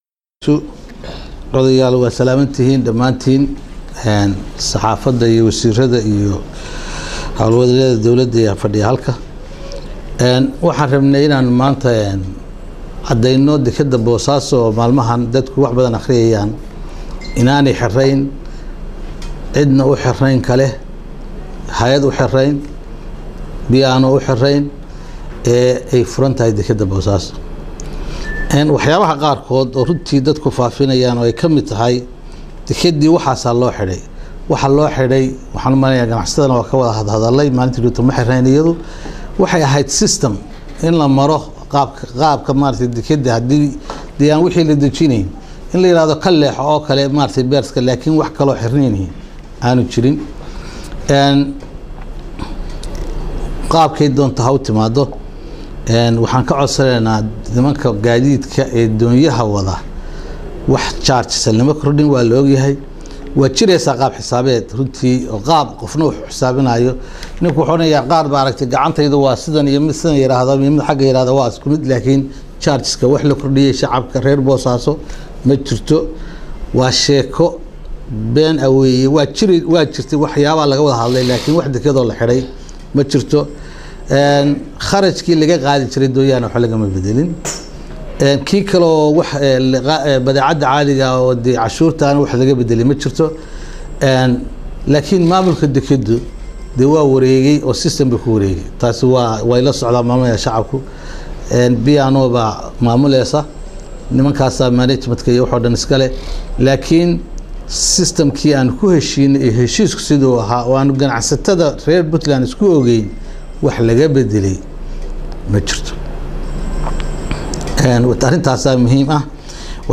30 Okt 2017 (Puntlnades) Xubno katirsan Golaha xukuumadda Puntland iyo maamulka Gobalka Bari oo warbaahinta la hadlay ayaa sheegay inaan jirin wax khidmad ah oo lagu kordhiyey doonyaha iyo maraakiibta Ganacsi ee ku soo xirta Dekedda magaalada Bosaso.
Dhagayso Wasiirka DekedahaPuntland